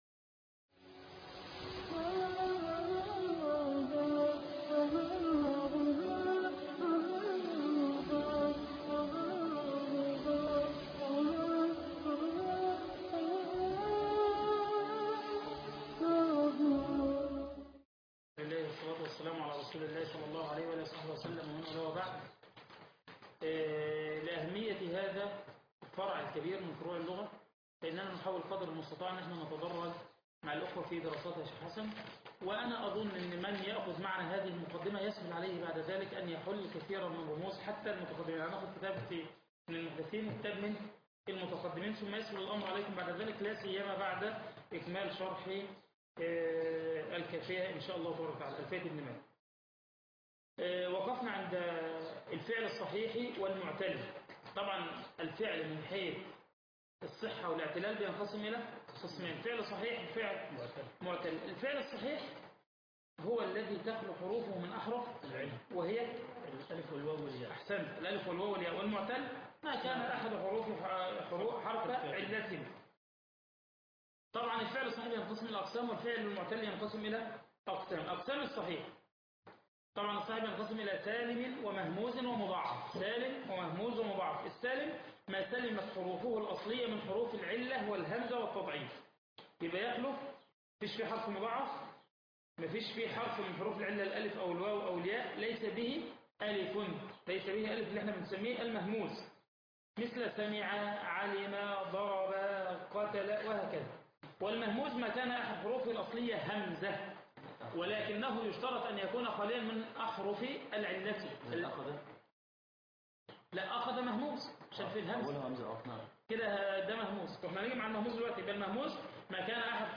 تيسير الصرف -المحاضرة الأولى